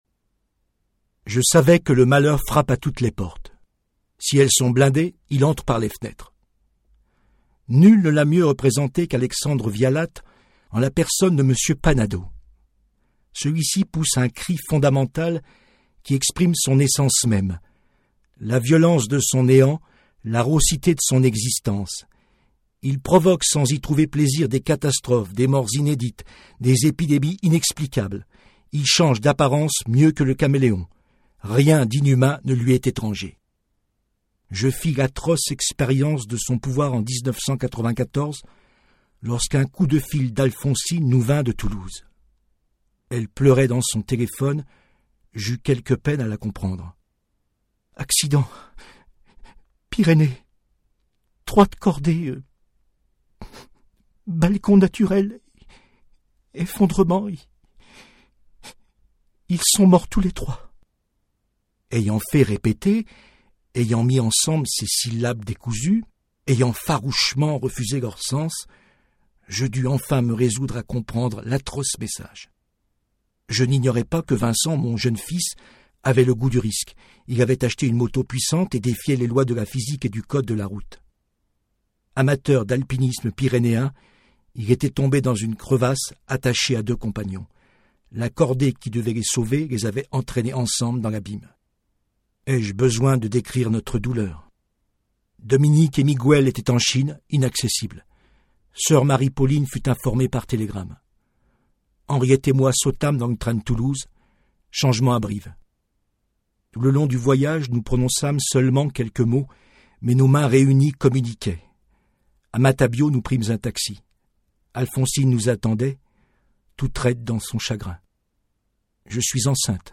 Voix d'homme en français ⋆ Domino Studio
AUDIO-BOOK